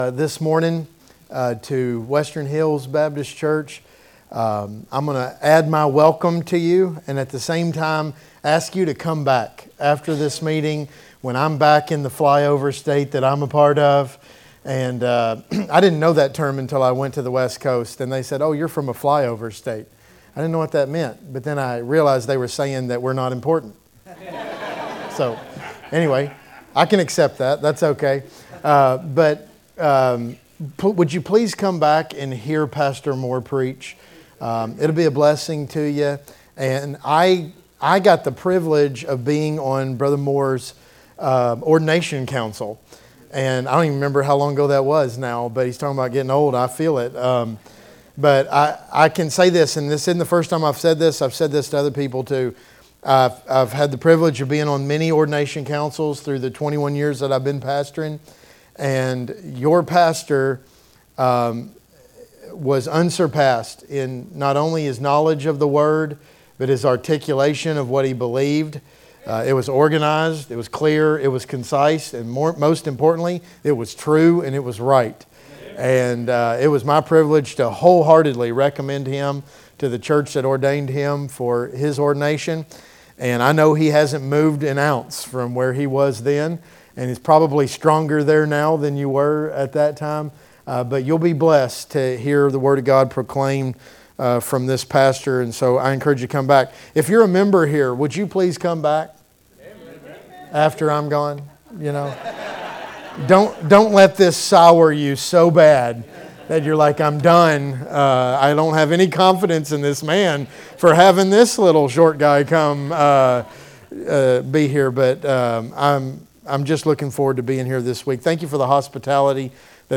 Guest Preacher